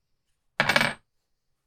Coin Drop - 1
buy coin coins ding drop dropping falling finance sound effect free sound royalty free Sound Effects